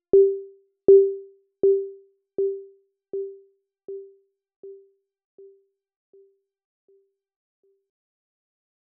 The pingpong mode is for the delay effect; it means each delay will alternate between left and right.
I don’t have a Digitakt, so I can’t test your exact situation, but here’s what the pingpong delay on my Digitone sounds like, with the following settings:
Time: 48
Width: 63
Feedback: 50
Recorded via USB, but sounds the same in headphones.